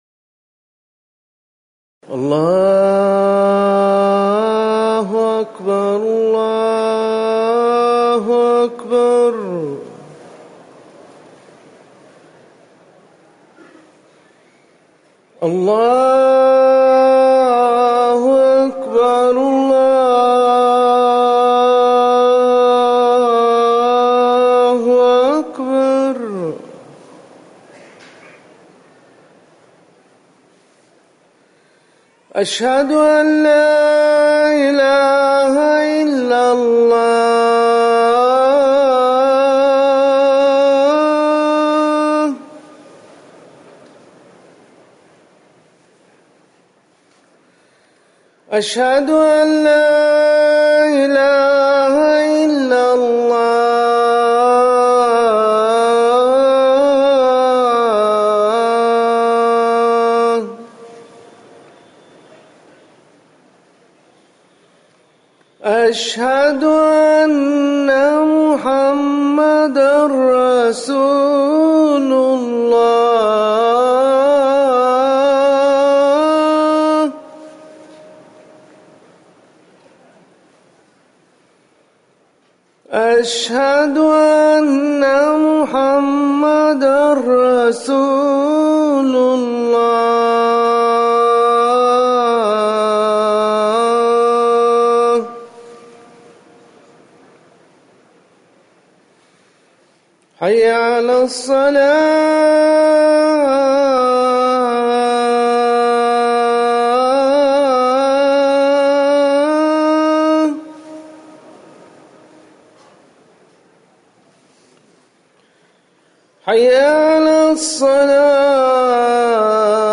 أذان المغرب
تاريخ النشر ٢٠ محرم ١٤٤١ هـ المكان: المسجد النبوي الشيخ